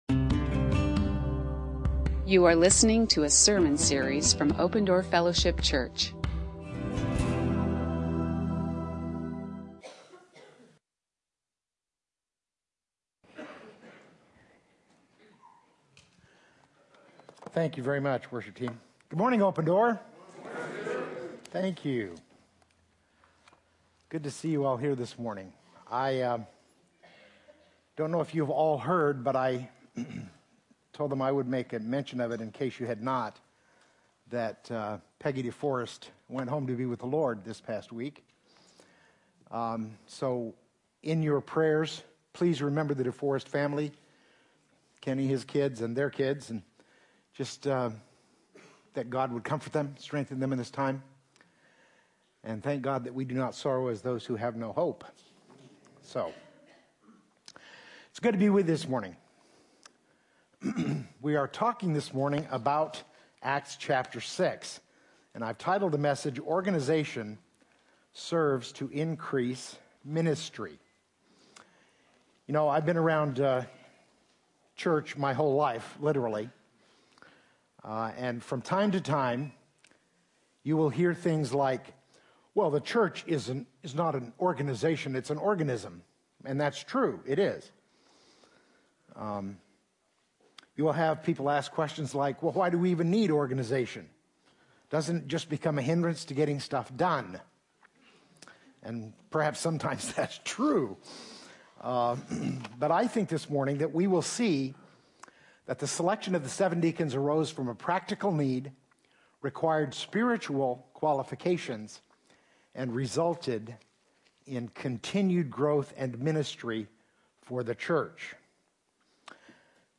You are listening to an audio recording of Open Door Fellowship Church in Phoenix, Arizona.